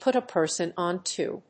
アクセントpùt a person ón to [ónto]…